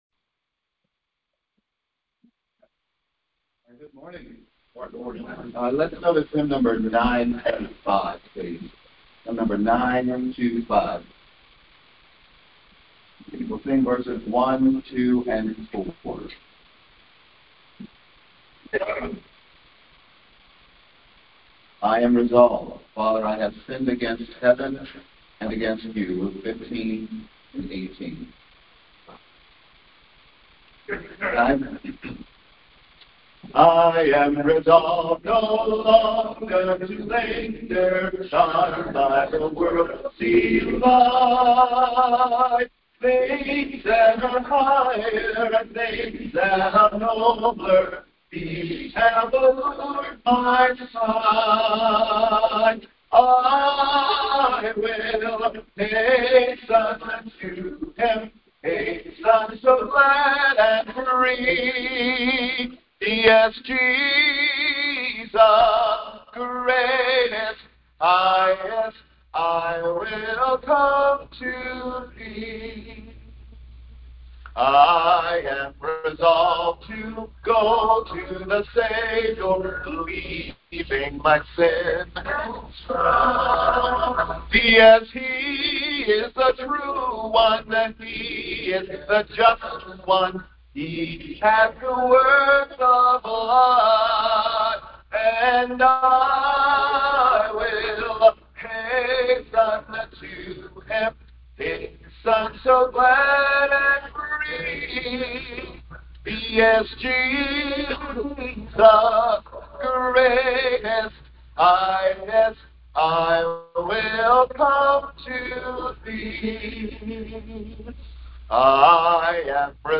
3.22.26 Sunday Morning Service